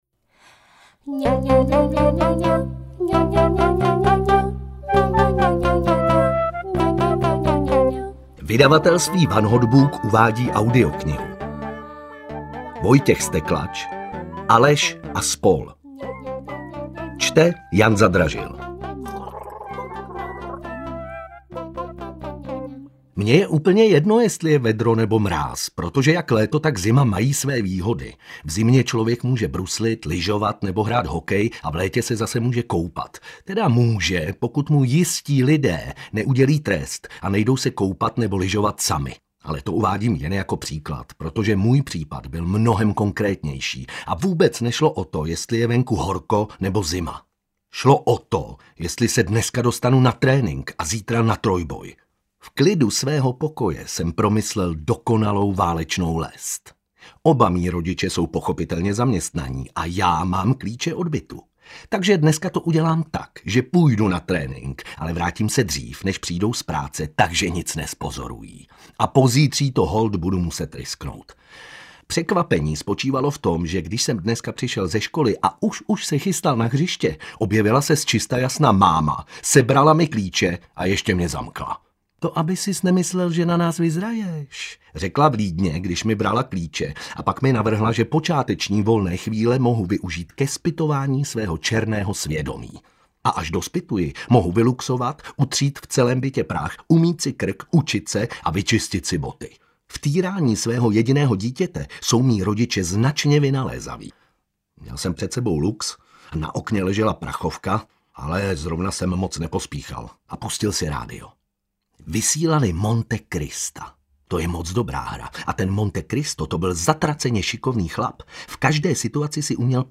Aleš & spol. audiokniha
Ukázka z knihy